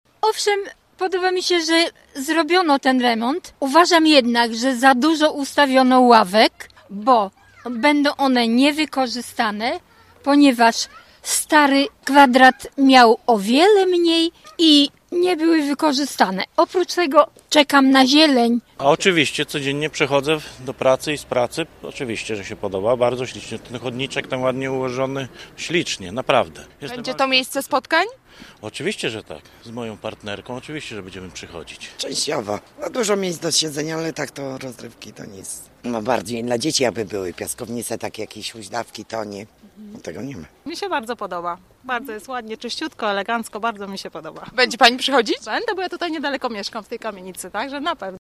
A to usłyszeliśmy dziś na ulicach Gorzowa.
kwadrat-sonda.mp3